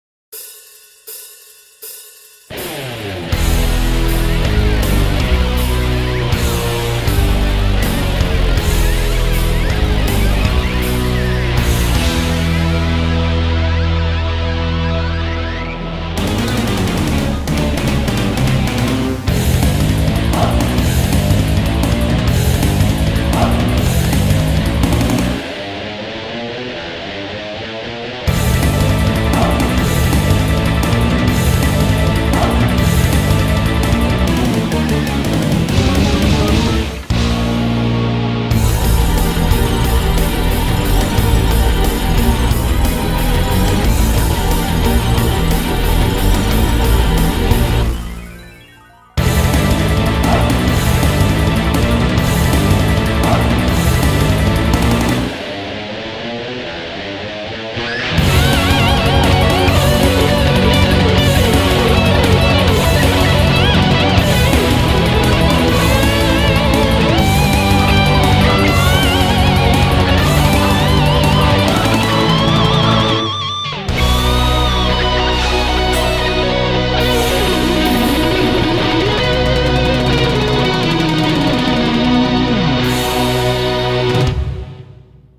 BPM80-300
Audio QualityPerfect (High Quality)
Commentaires[DARK PROGRESSIVE]